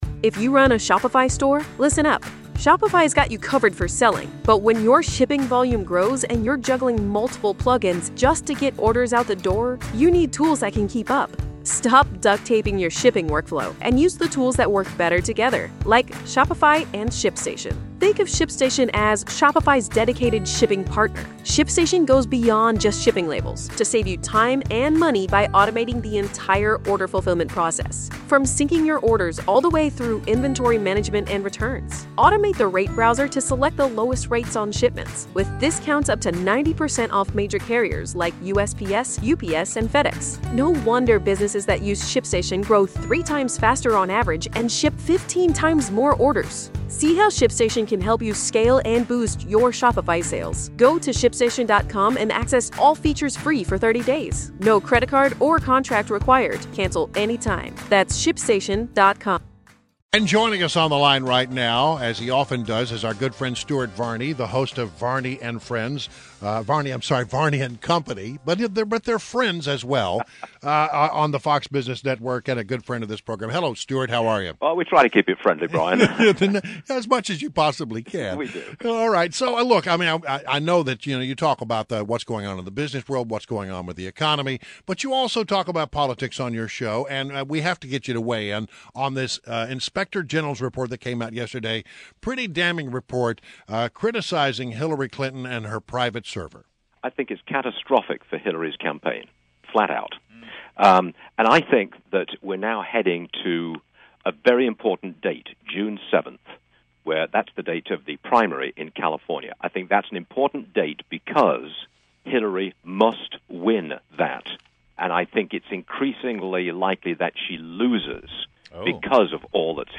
WMAL Interview - STUART VARNEY 05.26.16